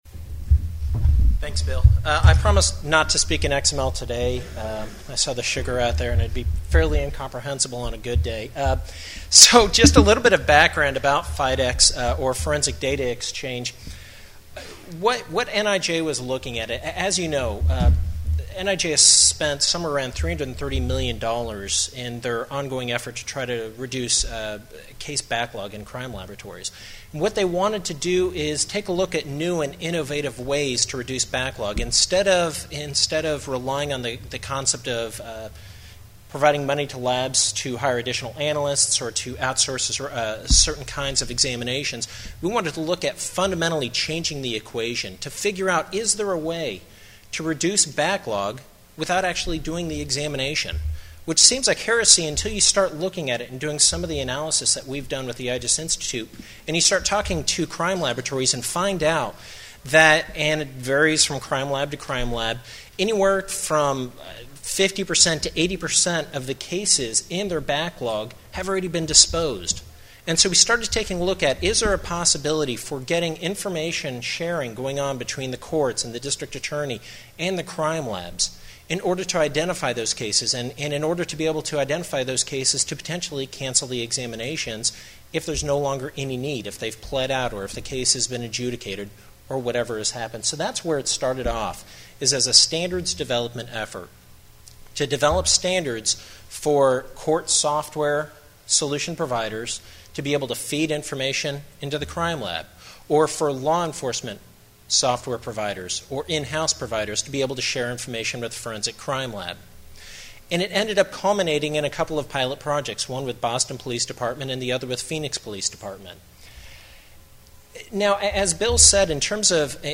NIJ Conference Panel